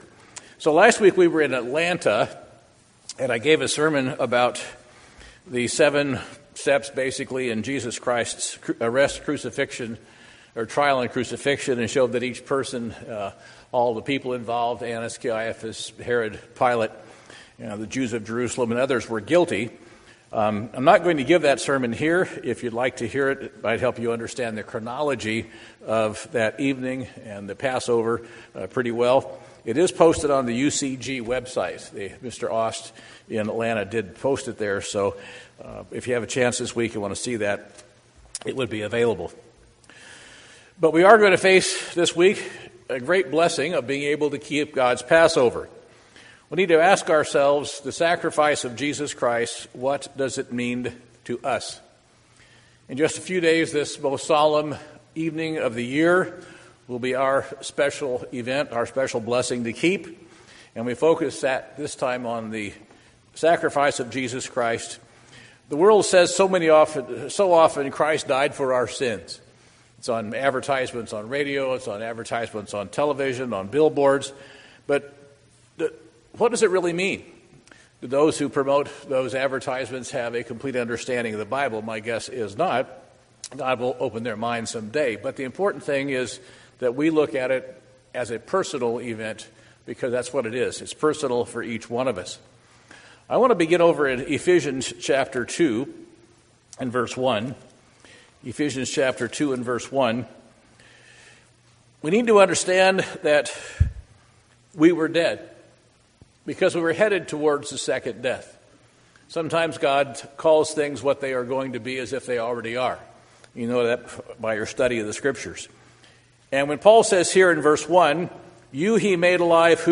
Sermons
Given in Portland, OR